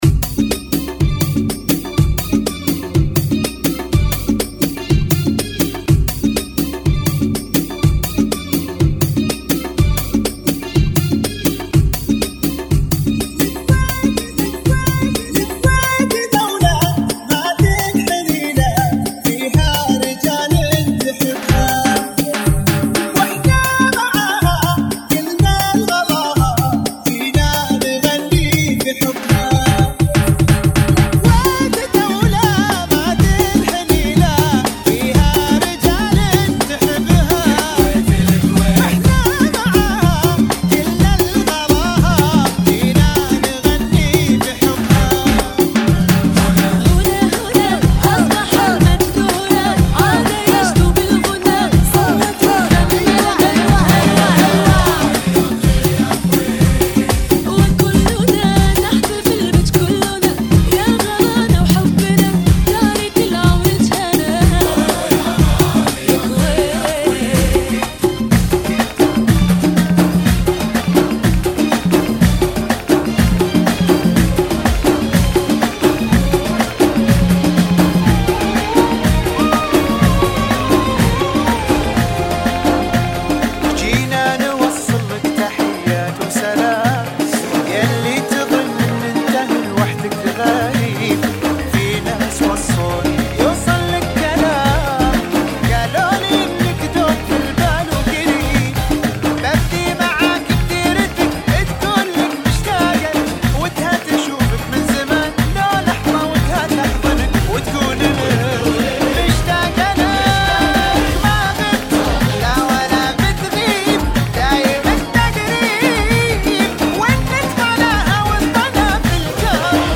Funky [ 123 Bpm ]